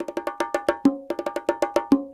44 Bongo 02.wav